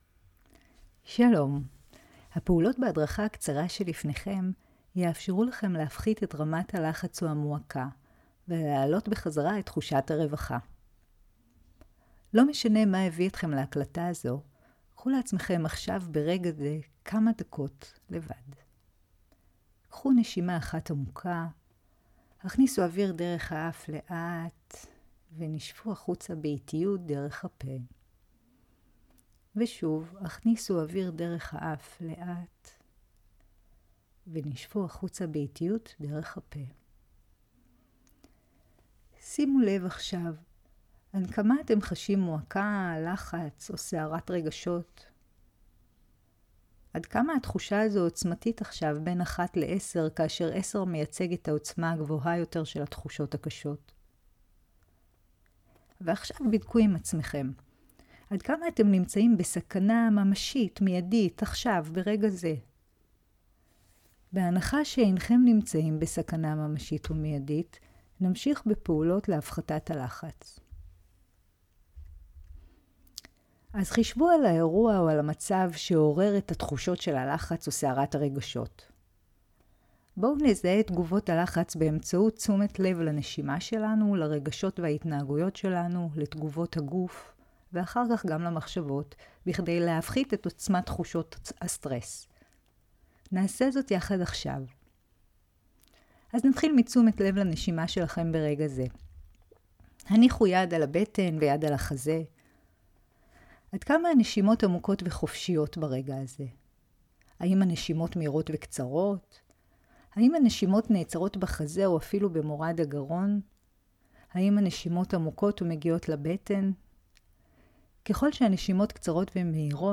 הדרכה קולית – אמנות האפשר